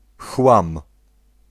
Ääntäminen
Ääntäminen US Tuntematon aksentti: IPA : /kɹæp/